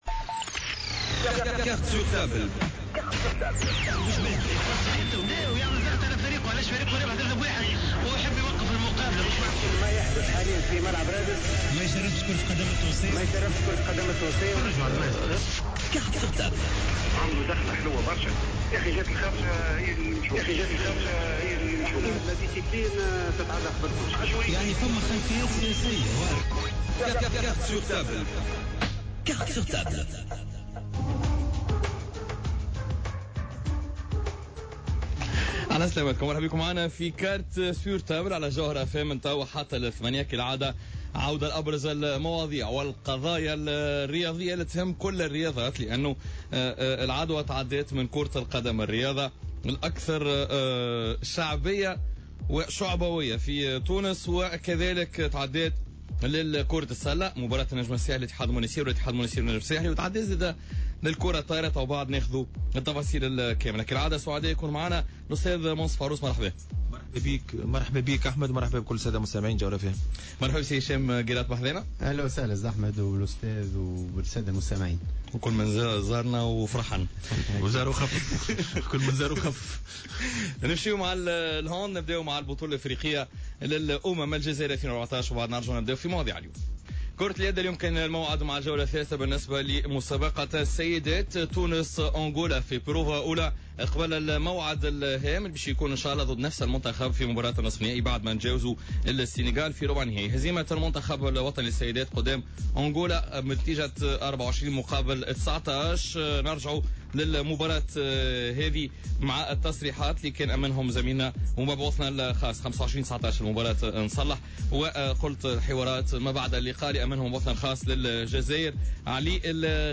reportages et interviews à l'appui